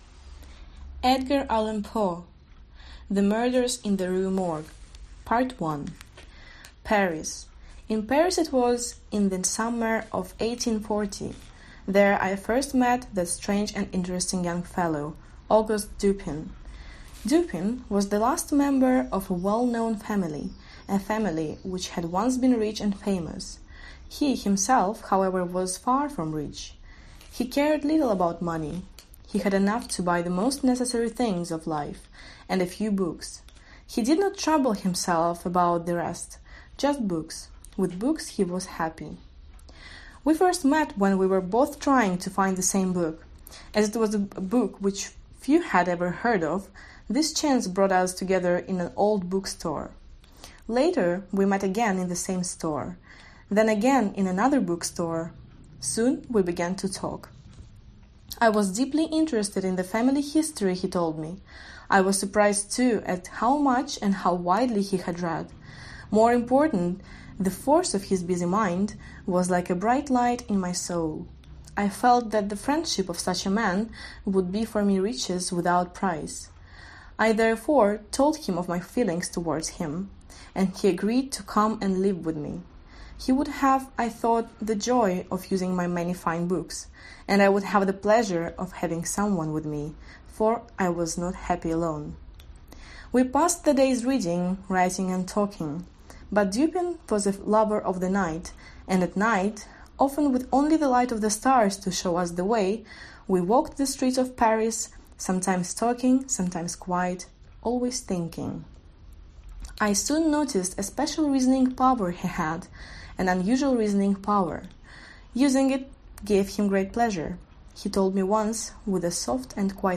Аудиокнига Убийство на улице Морг/The Murders in the Rue Morgue | Библиотека аудиокниг